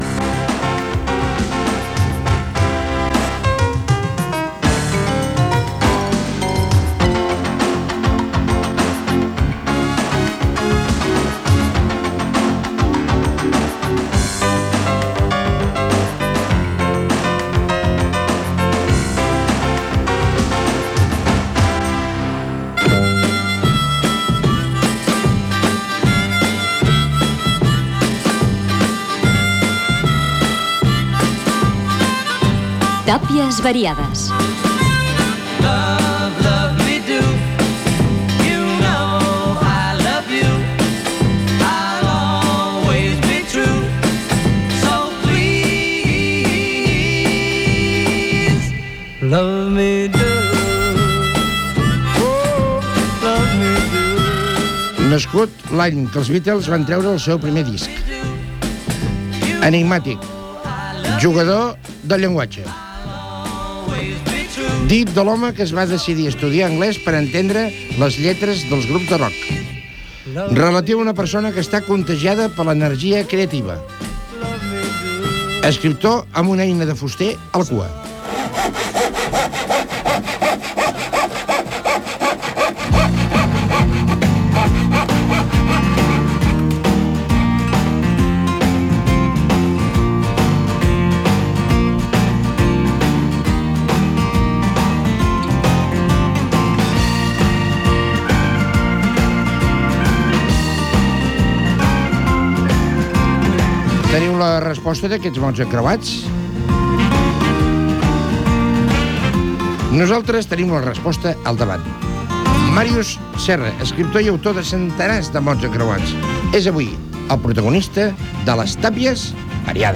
Careta del programa, presentació de l'invitat fent preguntes de definicions de paraules, telèfon de participació, entrevista a l'escriptor Màrius Serra, trucada telefònica
Entreteniment